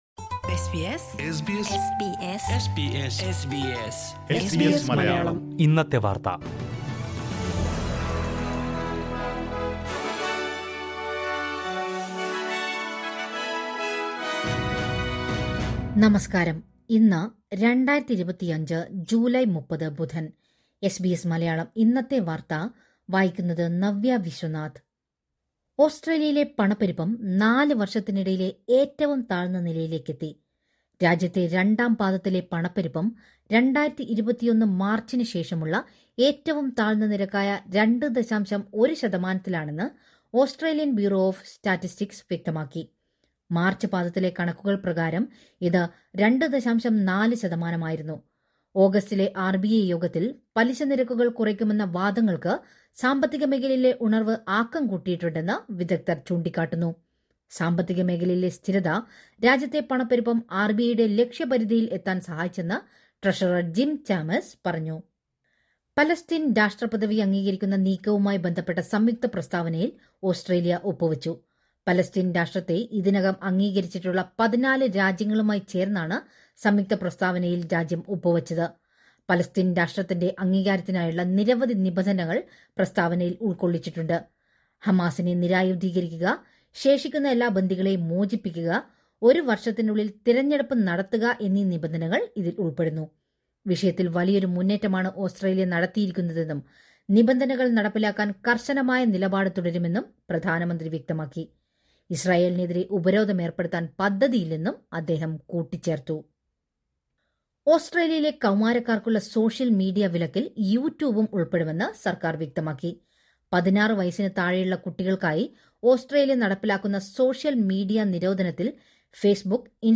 2025 ജൂലൈ 30ലെ ഓസ്‌ട്രേലിയയിലെ ഏറ്റവും പ്രധാന വാര്‍ത്തകള്‍ കേള്‍ക്കാം...